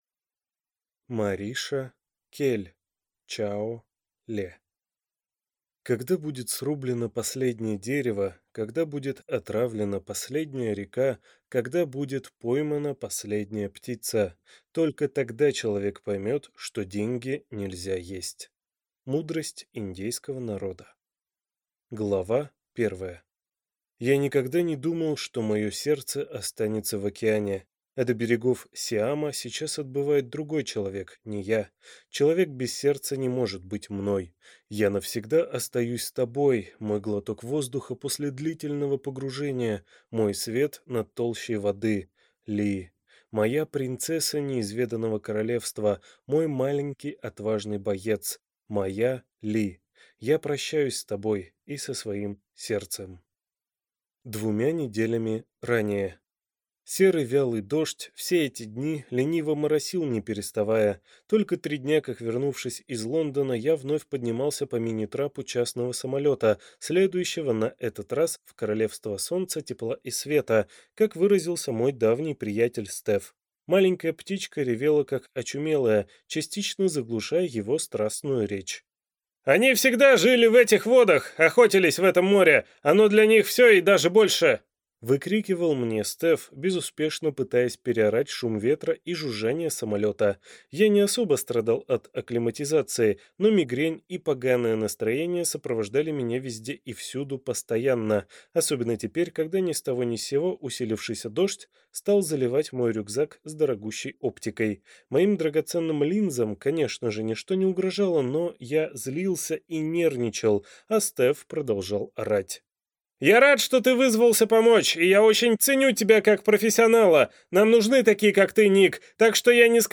Аудиокнига Чао Ле | Библиотека аудиокниг
Прослушать и бесплатно скачать фрагмент аудиокниги